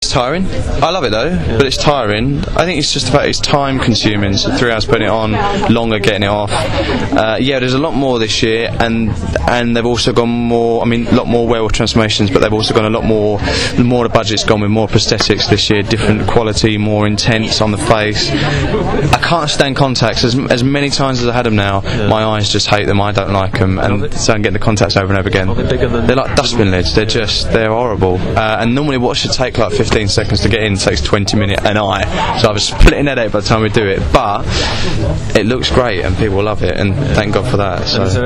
In the hours before the preview screening of episode one, I interviewed Russell (werewolf George), Aidan Turner (vampire Mitchell) and Lenora Crichlow (ghost Annie).